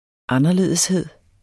Udtale [ ˈɑnʌˌleðˀəsˌheðˀ ]